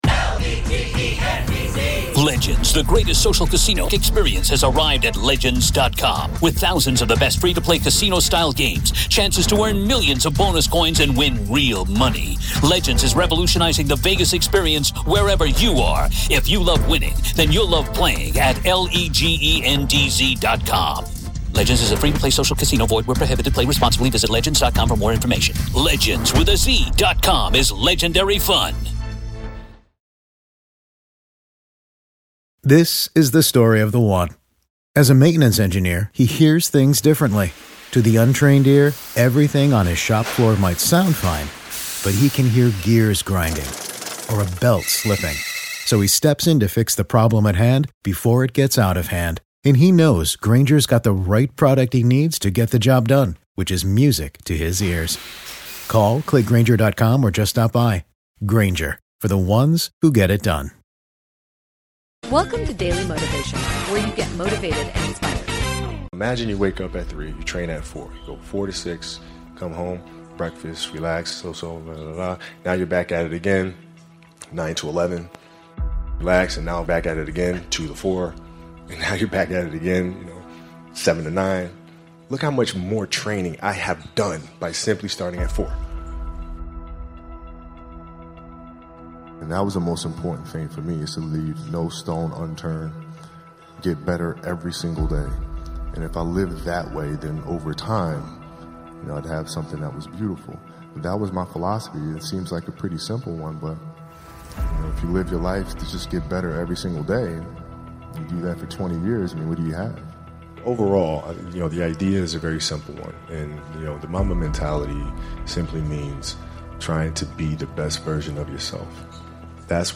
credit to the speaker: Kobe Bryant